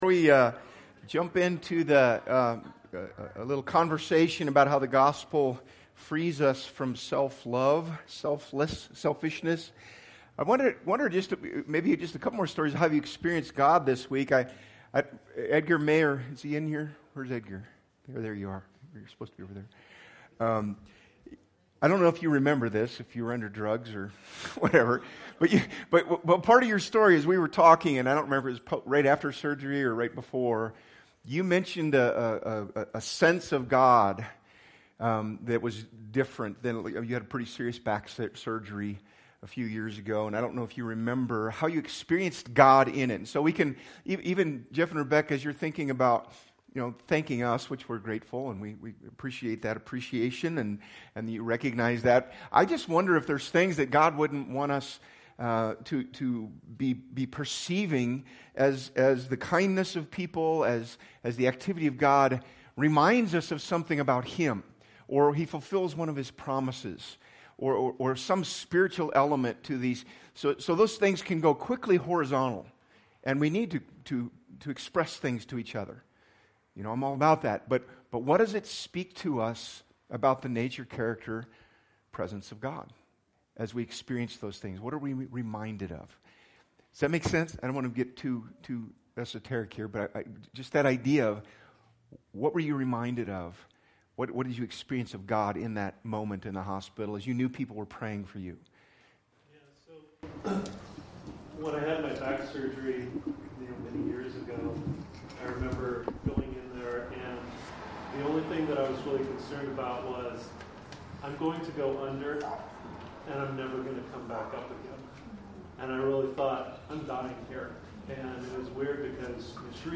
BG Archives Service Type: Sunday Speaker